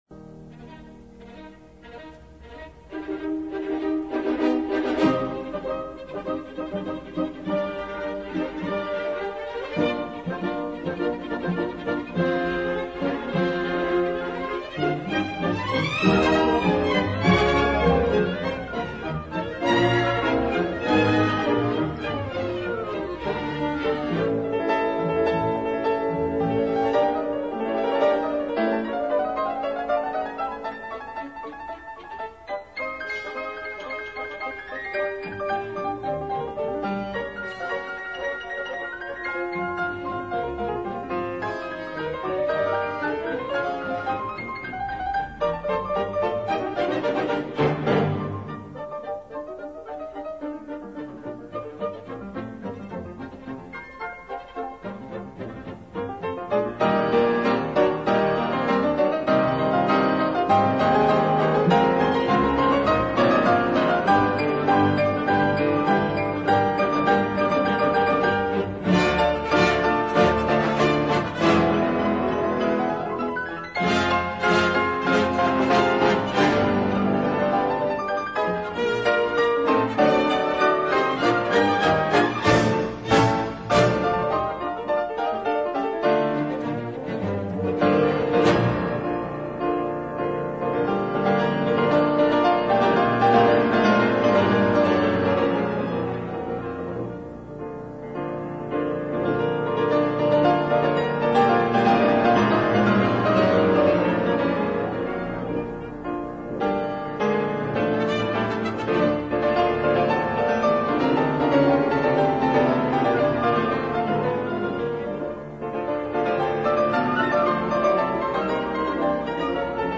پس از بخش لنتو، بار دیگر بخش “آلابره وه”، بی قرار شنیده می شود و کنسرتو با شور بسیار پایان می یابد.